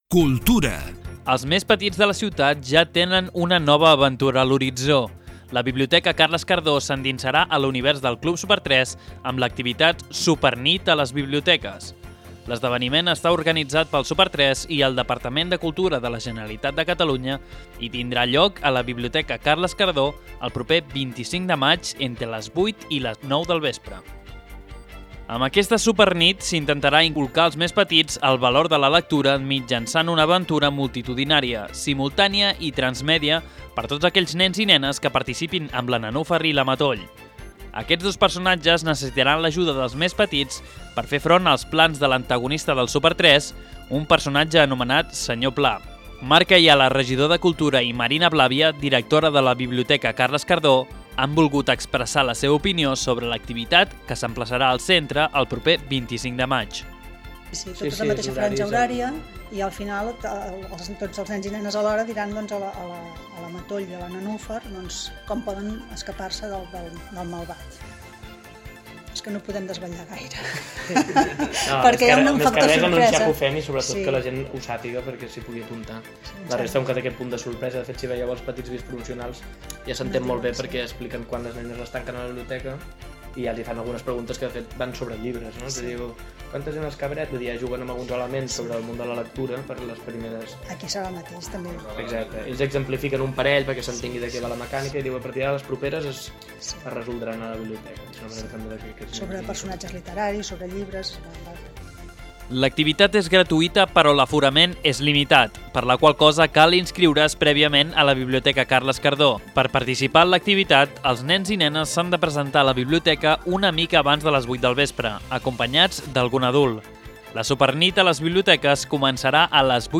Marc Ayala, regidor de Cultura